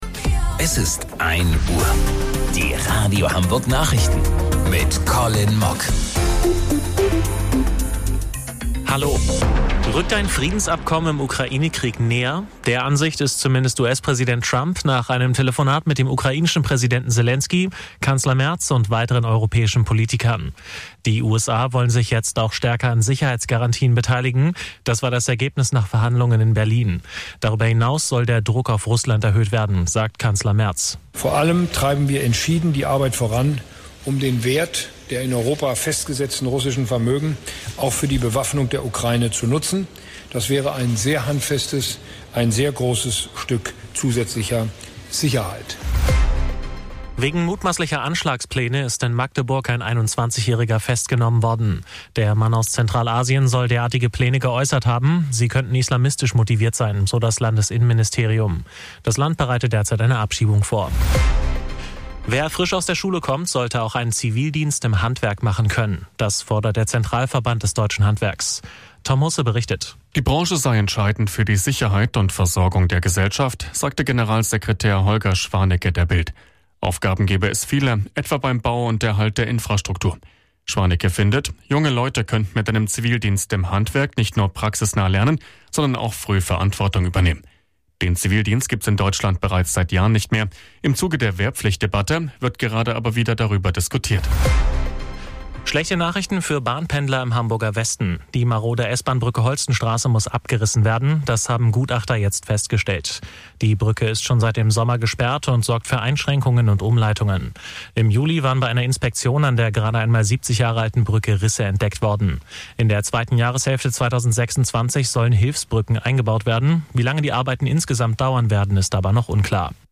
Radio Hamburg Nachrichten vom 16.12.2025 um 01 Uhr